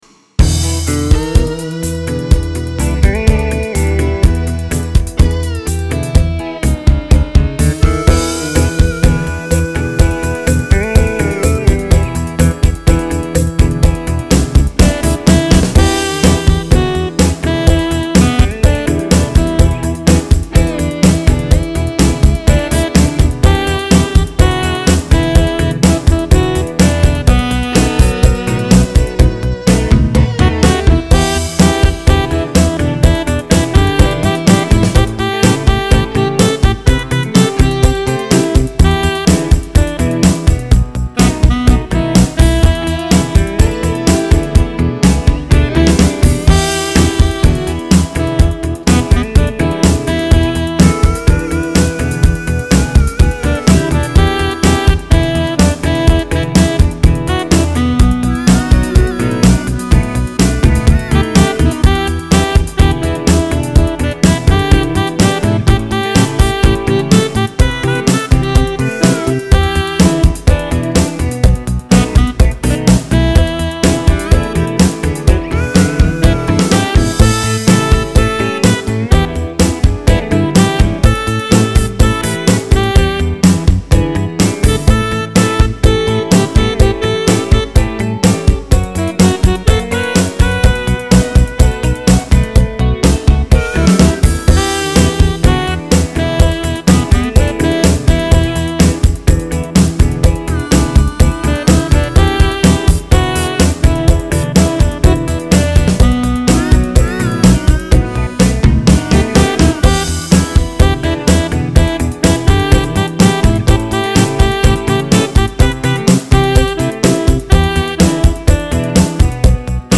This song is the epitome of country rock.